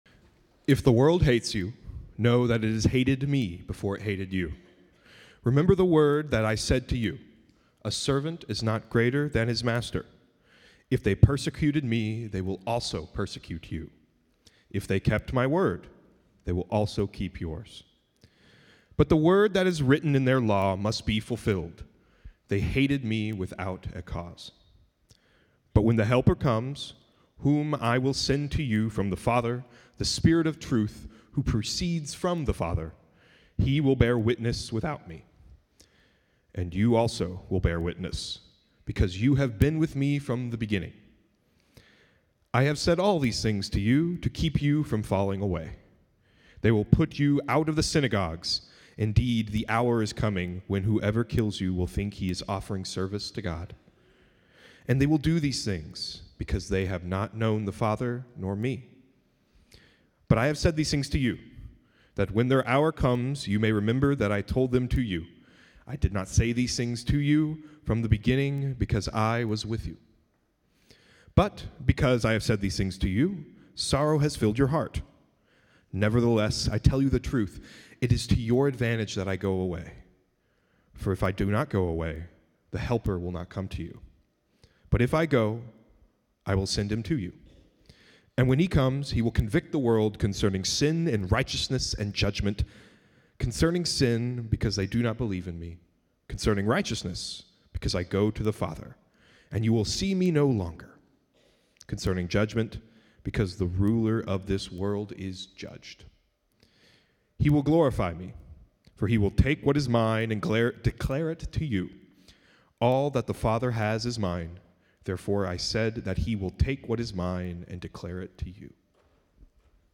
Join us each week as we dive deep into the Word of God and explore the life-transforming message of Jesus and the grace He offers us. In each episode, we bring you the dynamic and inspiring Sunday messages delivered by our passionate and knowledgeable pastors.